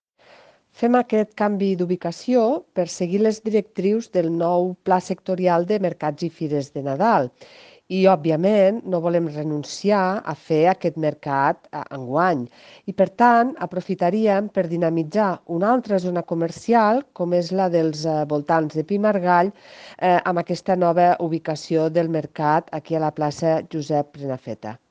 tall-de-veu-de-marta-gispert-sobre-el-canvi-dubicacio-del-mercat